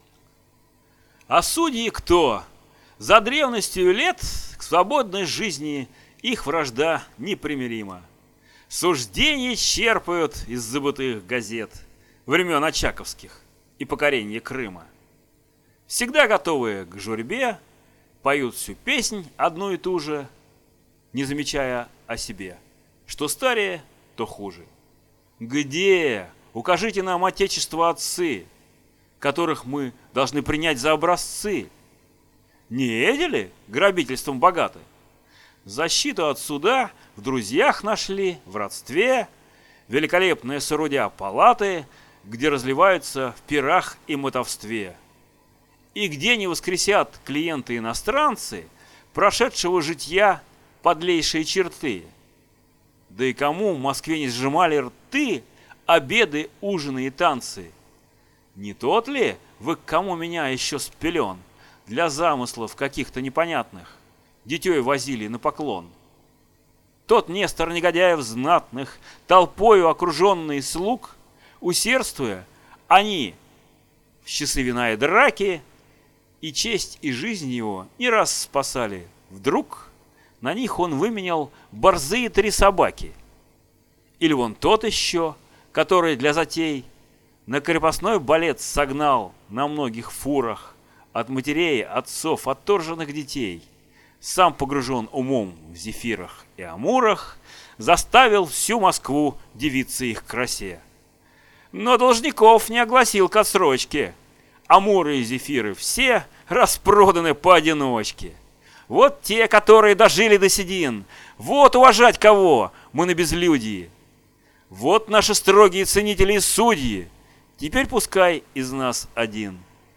Монолог Чацкого.